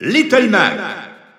Announcer pronouncing Little Mac in French.
Little_Mac_French_Announcer_SSBU.wav